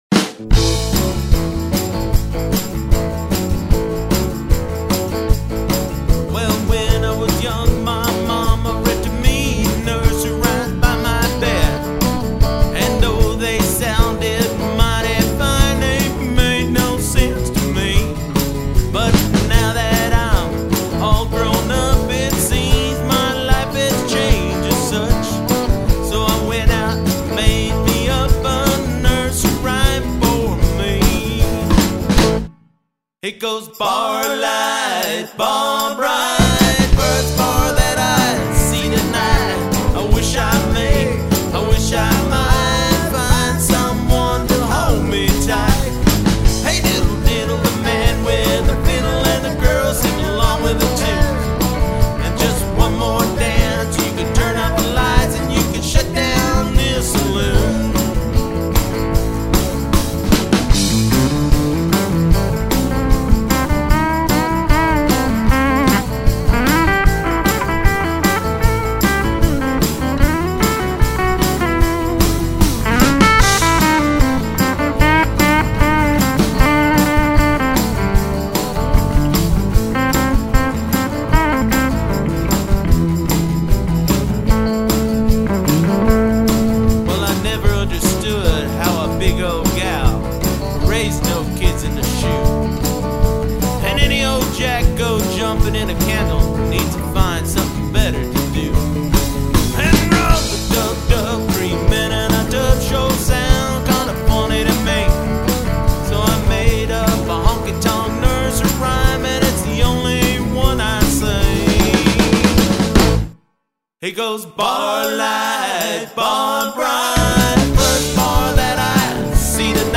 Lead vocals rythm Guitar
Lead guitar
Percussion
Drums